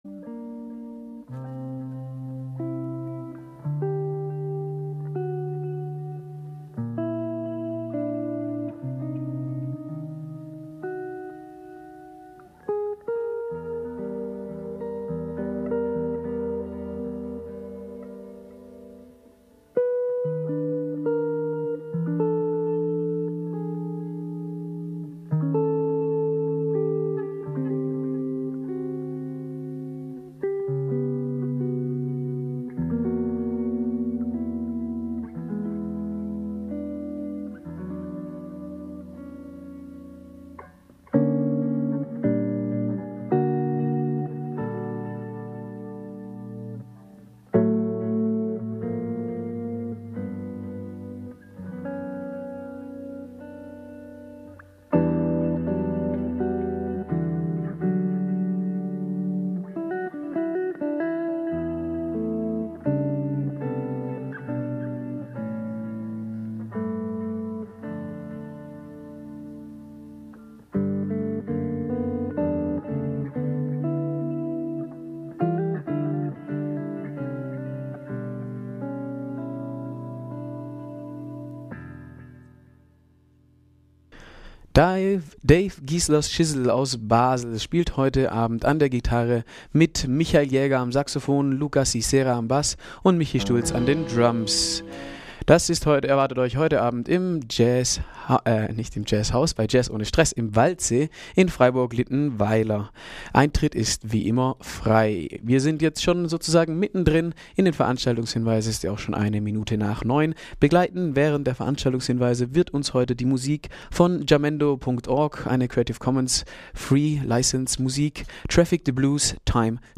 mit o-ton S.Gigold (MeP)